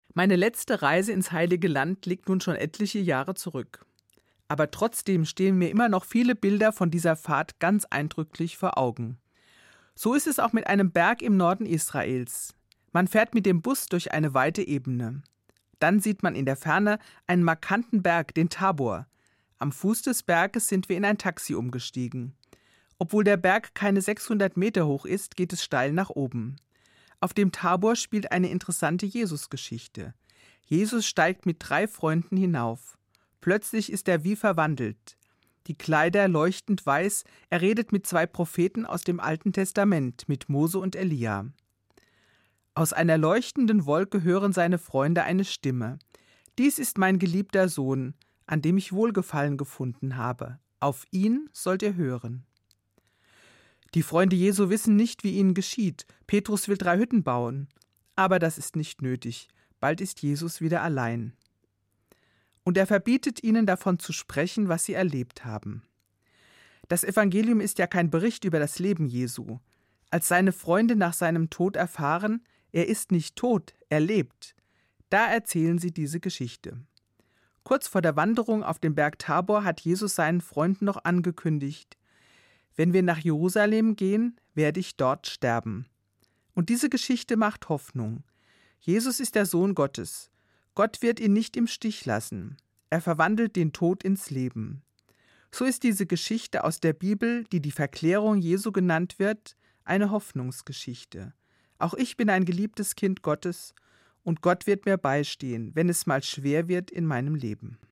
Katholische Gemeindereferentin im Ruhestand